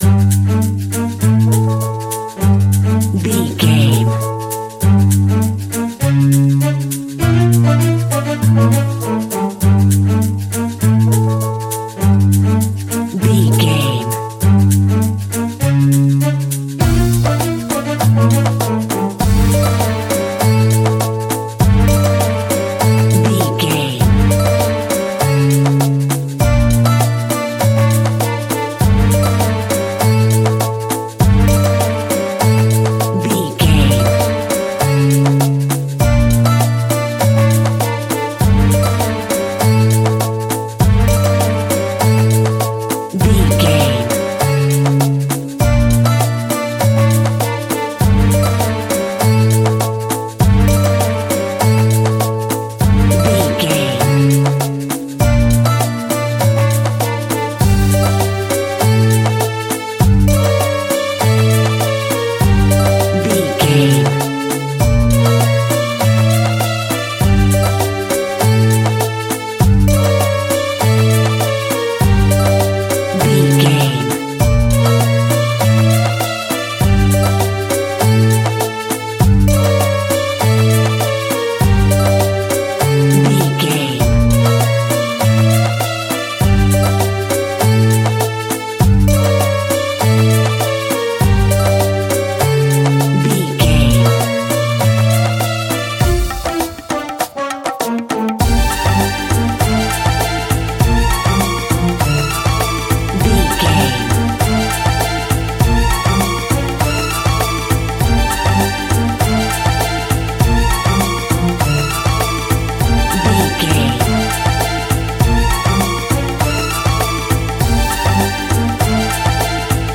Aeolian/Minor
World Music
percussion
congas
bongos
djembe